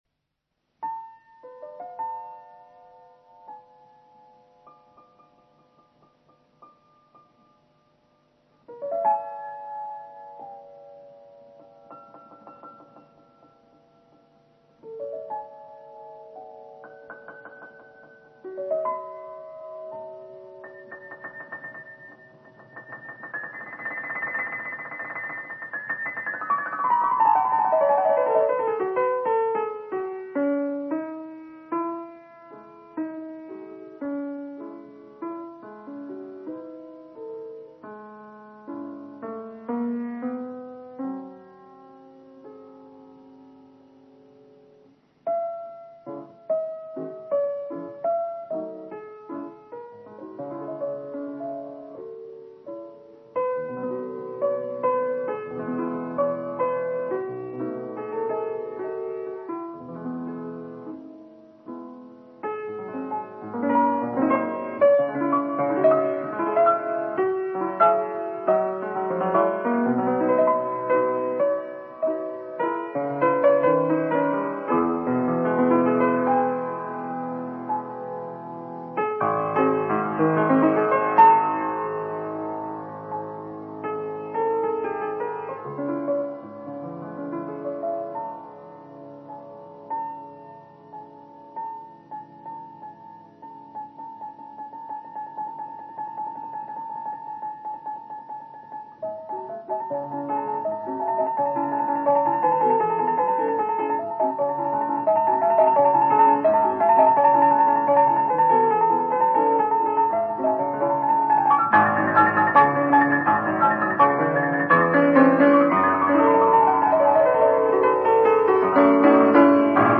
Не менее знаменита и фортепианная транскрипция алябьевского «Соловья», в которой Лист сумел уловить и передать красоту русского романса.